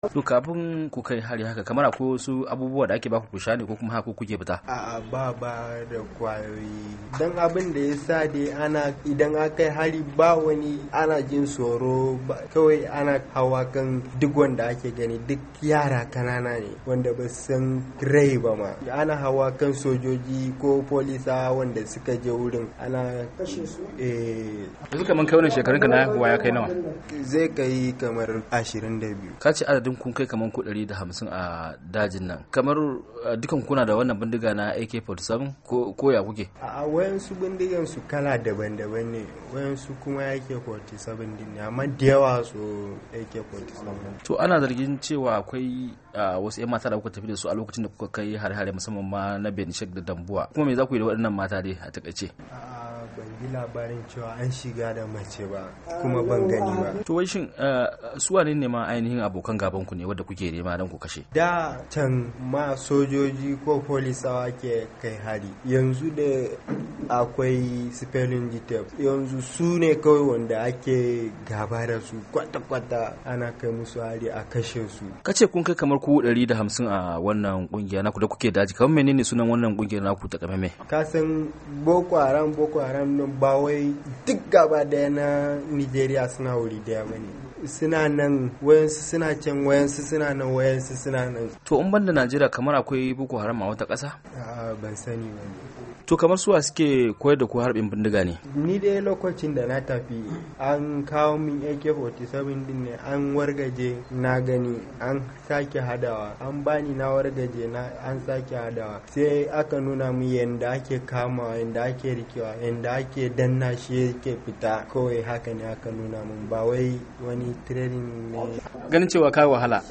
A kashi na biyu na hira da 'yan jarida, dan Boko Haram da aka kama yayi magana kan yadda suke samun makamai, da horaswa da rayuwa cikin daji